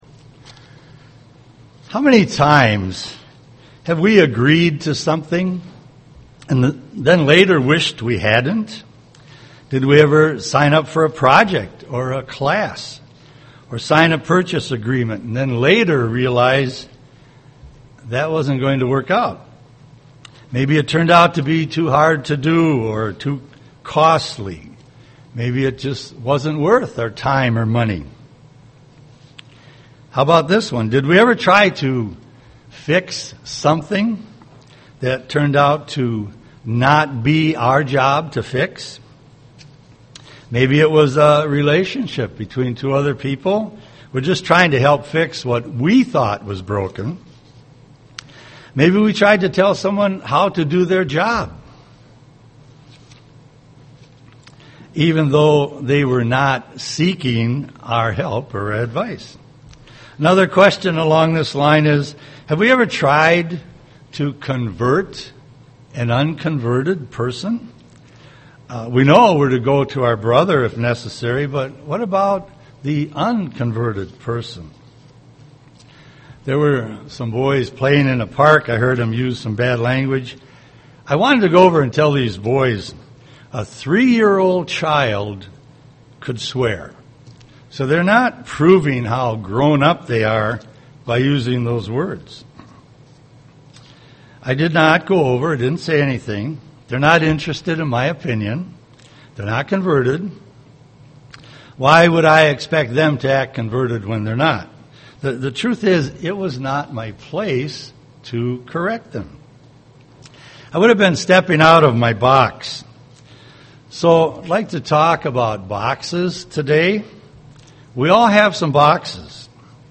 Given in Twin Cities, MN
UCG Sermon reap what you sow Studying the bible?